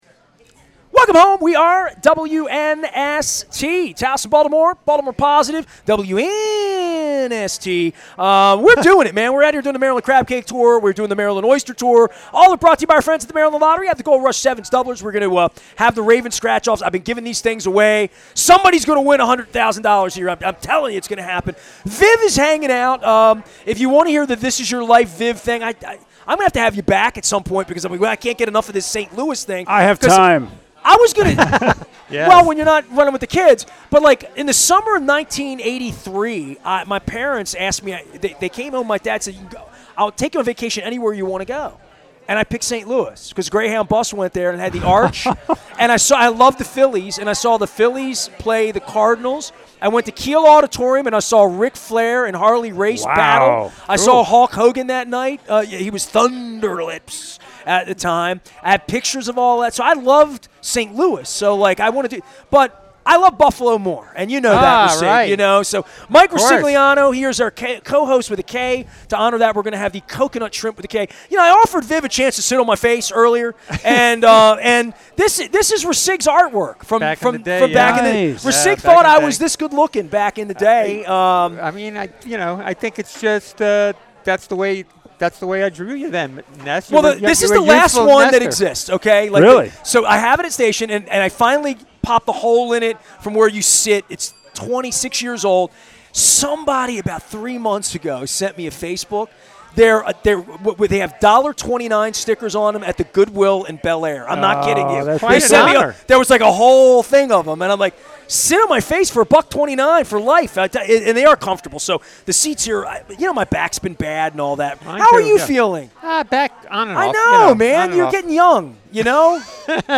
at Koco's Pub for a candid chat about Baltimore sports journalism – past, present and future.